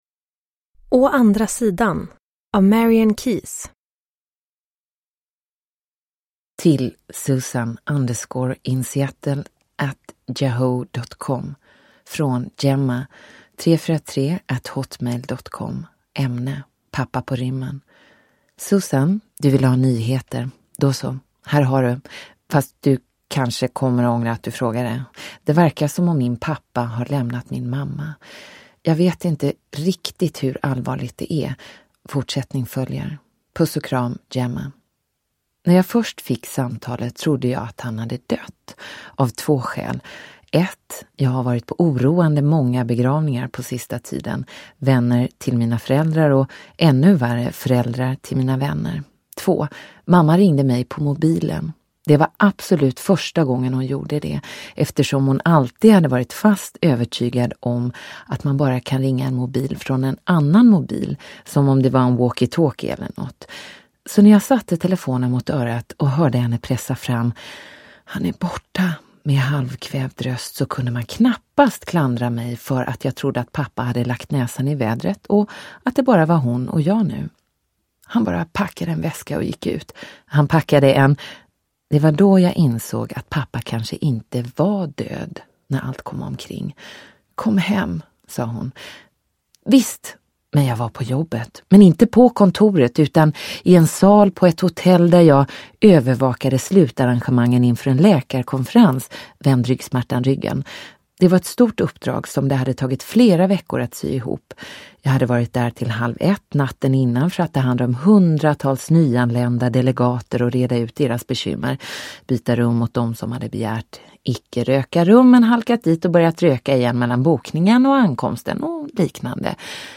Å andra sidan – Ljudbok – Laddas ner